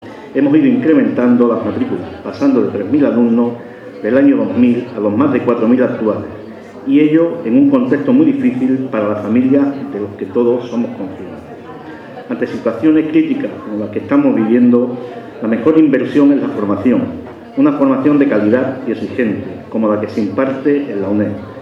Loaiza discurso apertura curso UNED
Acto inaugural del Curso en la UNED
El Centro Asociado de la Universidad Nacional de Educación a Distancia (UNED) en Cádiz ha celebrado la apertura del curso académico 2013/2014 en un acto solemne celebrado en el Salón Regio del Palacio Provincial de la Diputación y presidido por el titular de la misma, José Loaiza García.